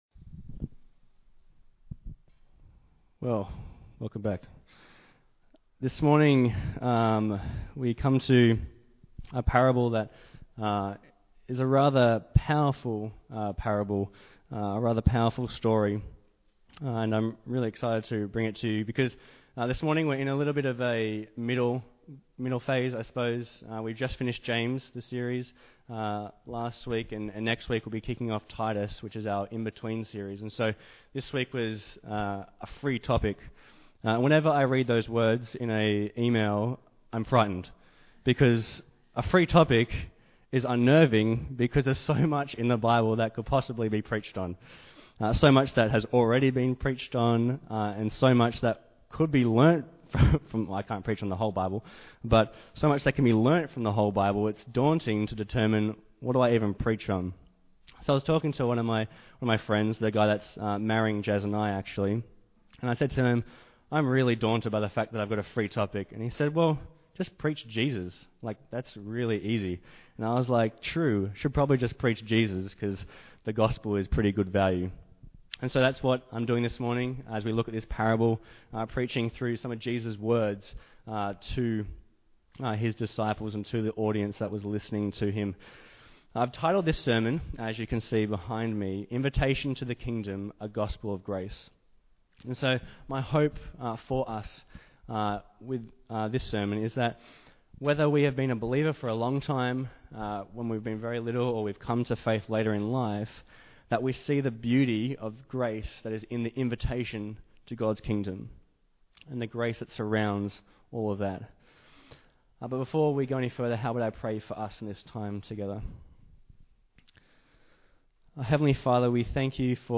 Bible Text: Matthew 20:1-16 | Preacher